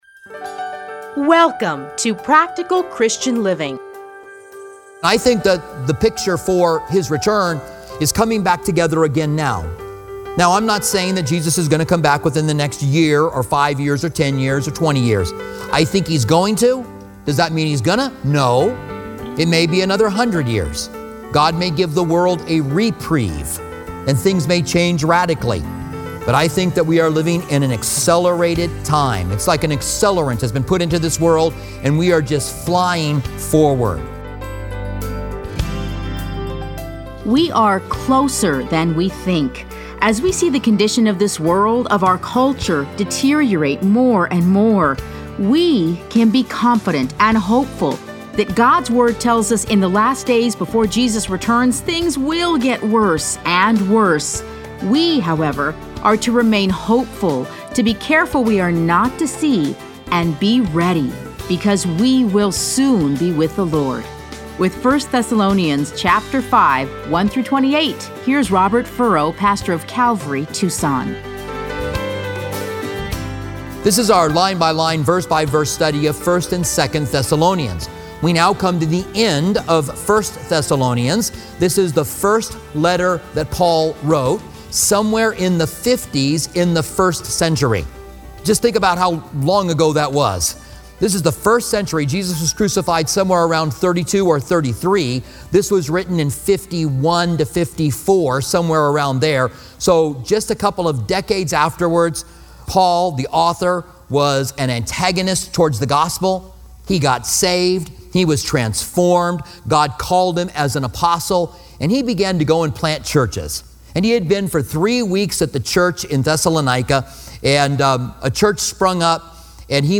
Listen to a teaching from 1 Thessalonians 5:1-28.